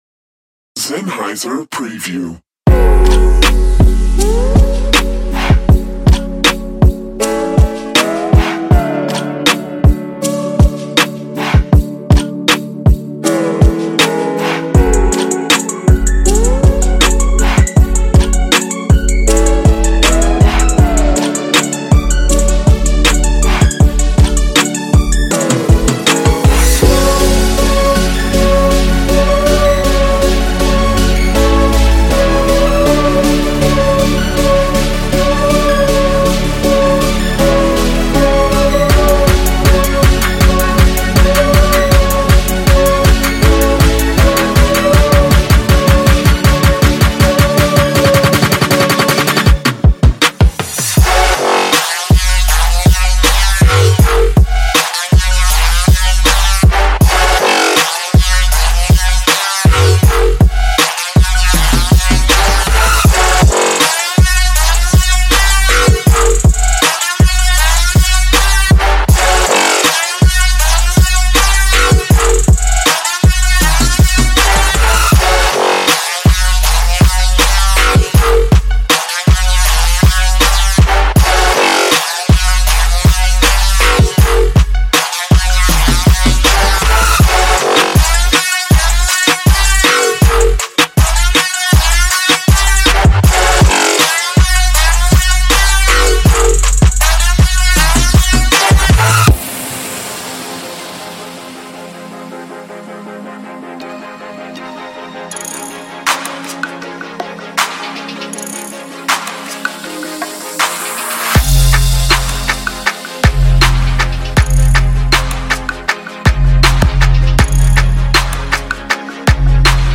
Tempo – 92bpm – 169bpm